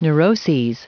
Prononciation du mot neuroses en anglais (fichier audio)
Prononciation du mot : neuroses